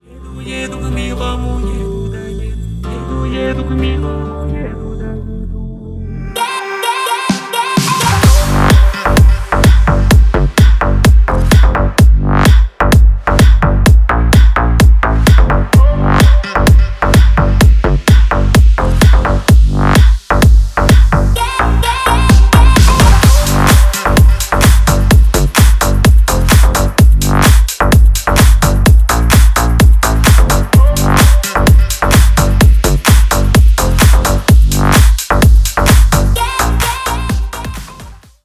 клубные , ремиксы